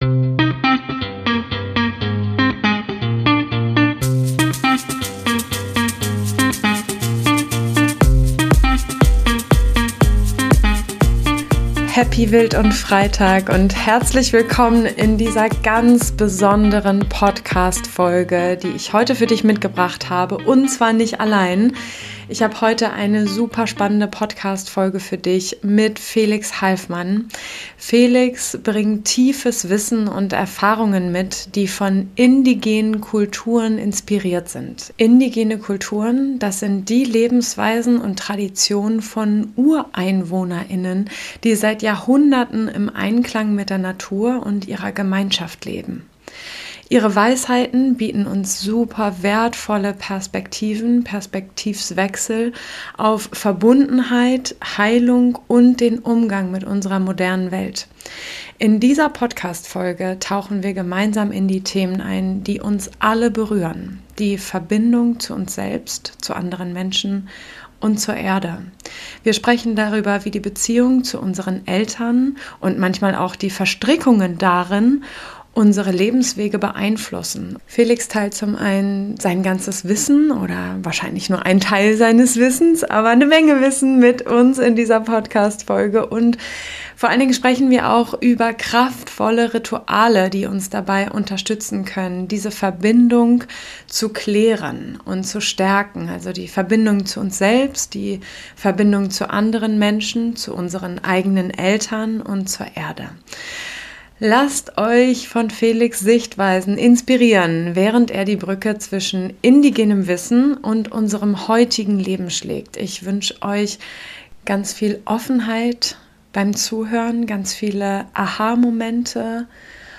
Heilende Verbindungen aus der Perspektive indigener Kulturen ~ Beziehungspodcast